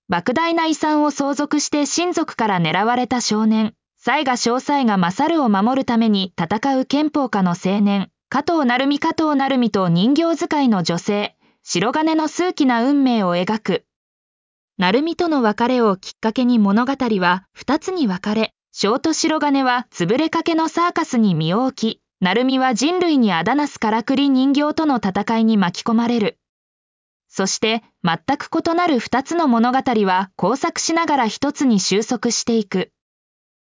ナレーション 音読さん